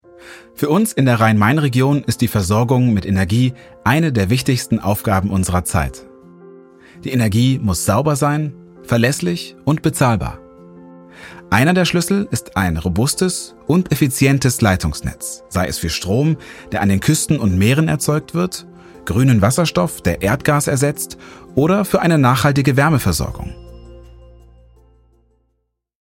Imagevideo
Meine stimmcharakteristik ist warm, klar und nahbar.
6-VoiceOver-Image.mp3